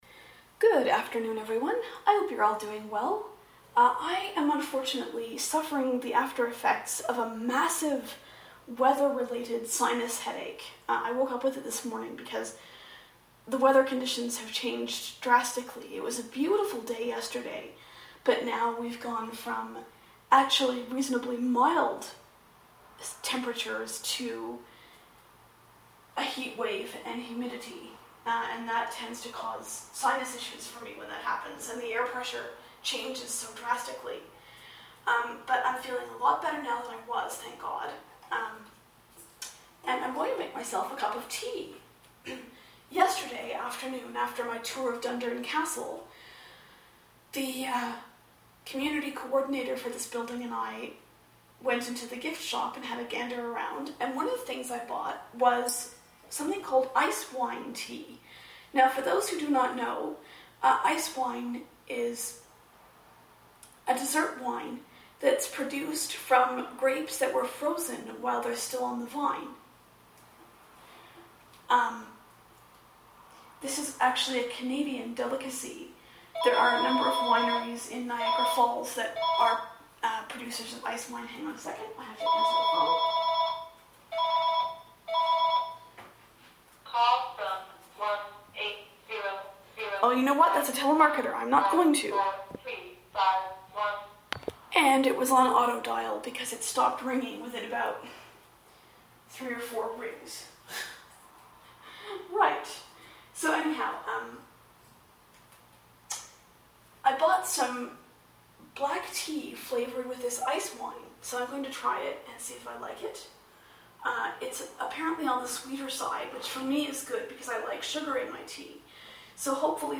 Talking About My Experiences Yesterday Whilst Making Ice Wine Tea